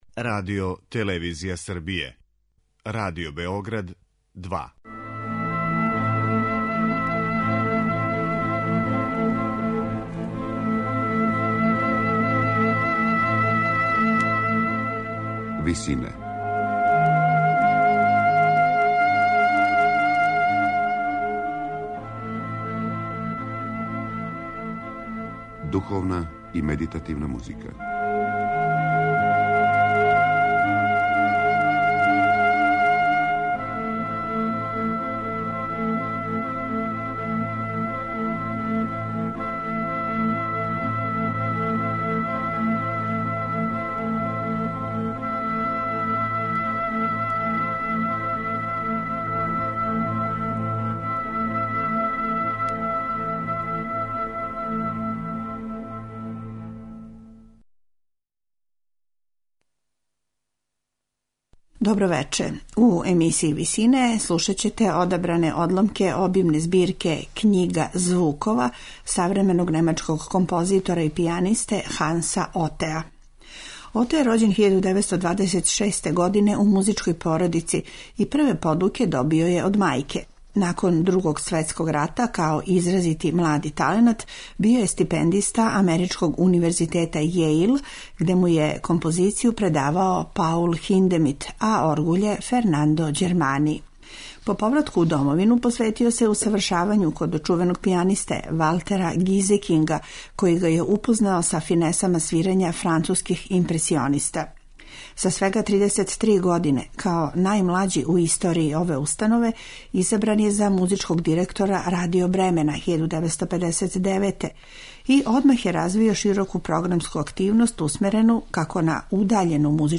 медитативне и духовне композиције